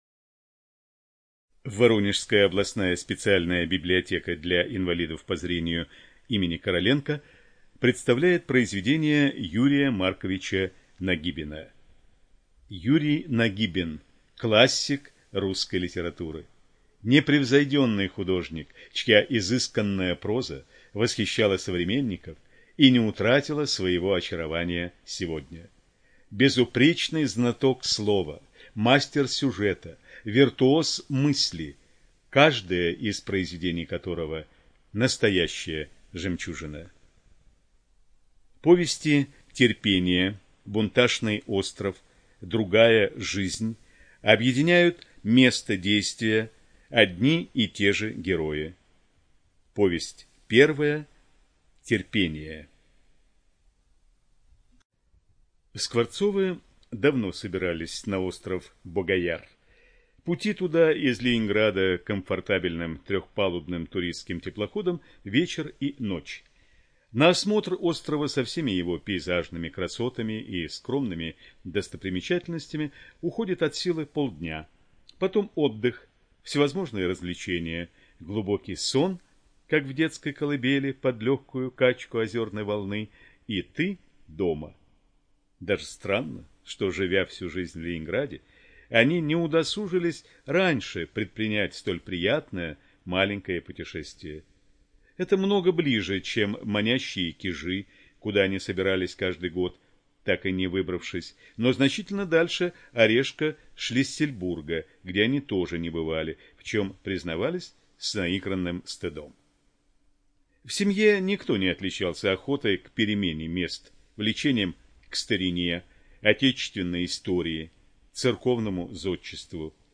Студия звукозаписиВоронежская областная библиотека для слепых имени Короленко